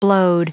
Prononciation du mot blowed en anglais (fichier audio)
Prononciation du mot : blowed